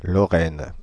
Ääntäminen
Ääntäminen Paris: IPA: [lɔ.ʁɛn] France (Île-de-France): IPA: /lɔ.ʁɛn/ Haettu sana löytyi näillä lähdekielillä: ranska Käännös 1.